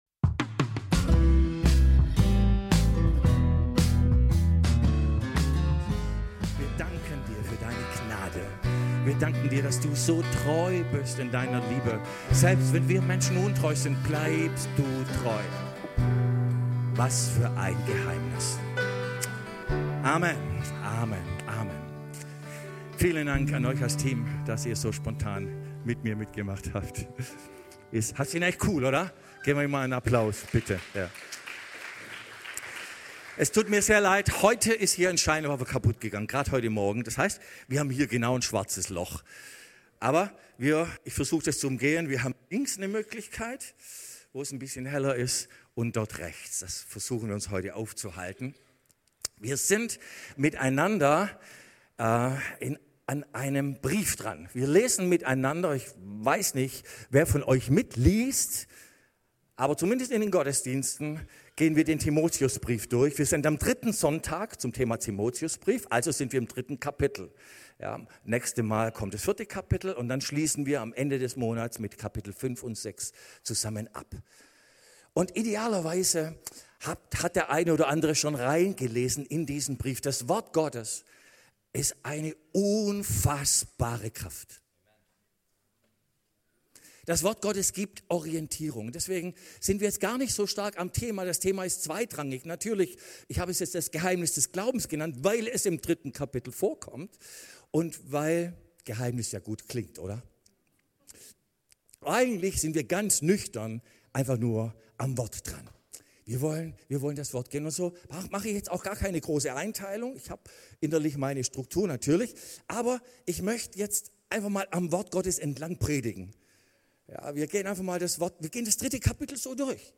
alle Predigten Das Geheimnis des Glaubens 19 Oktober, 2025 Serie: 1.